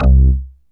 SYNTH BASS-1 0001.wav